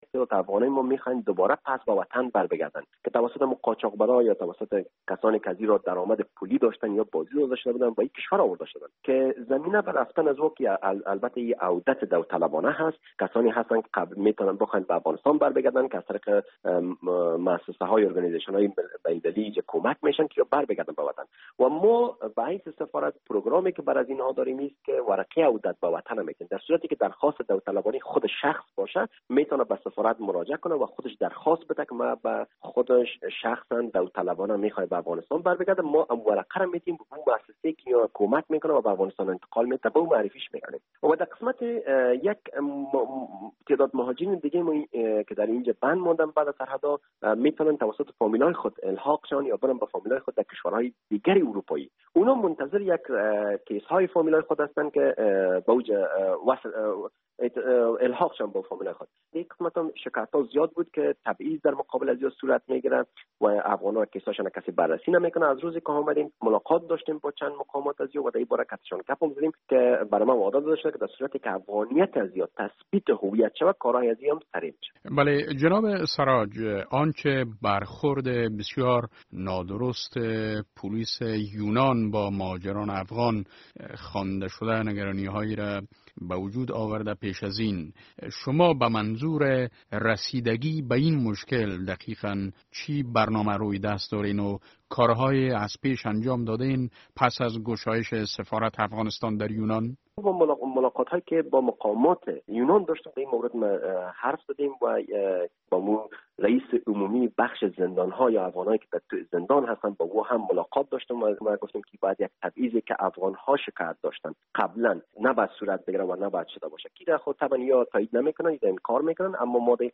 مصاحبه - صدا
این را سراج الحق سراج، شارژادفیر سفارت افغانستان در یونان به روز جمعه به رادیو آزادی گفت.